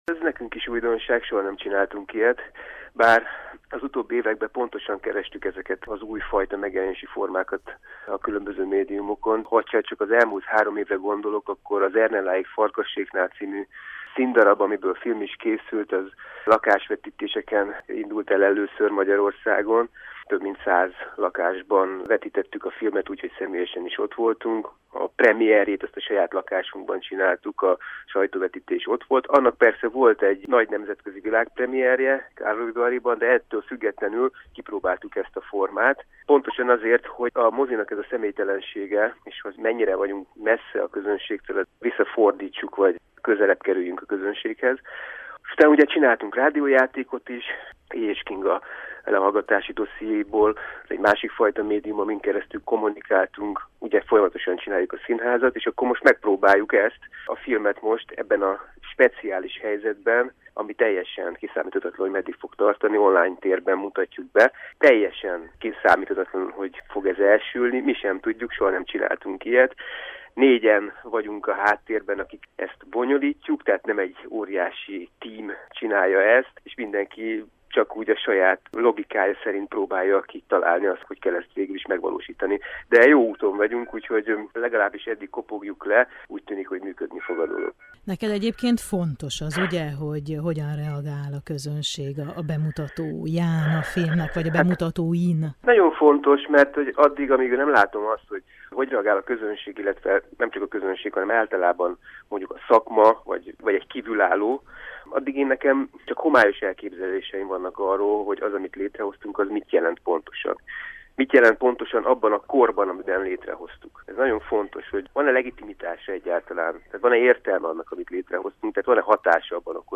A produkció vitatott társadalmi kérdéseket is boncolgat. Hajdú Szabolcs rendezővel beszélgetünk.